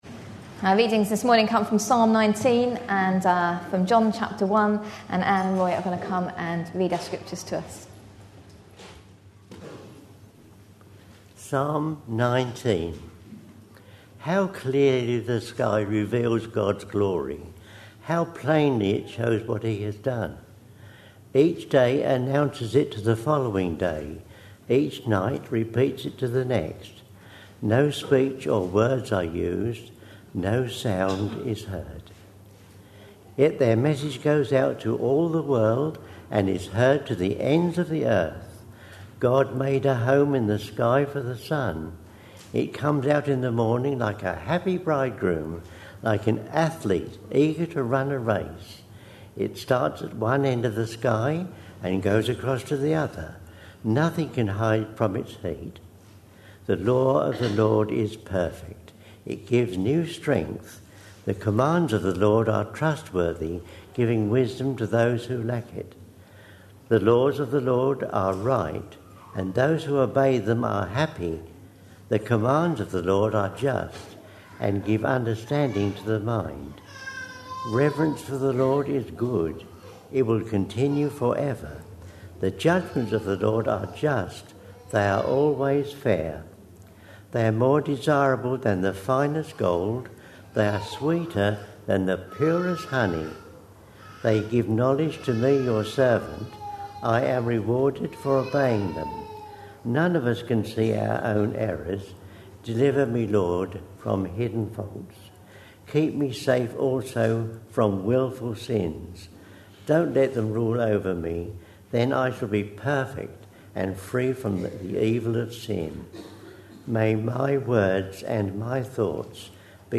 A sermon preached on 28th August, 2011, as part of our Psalms we Love series.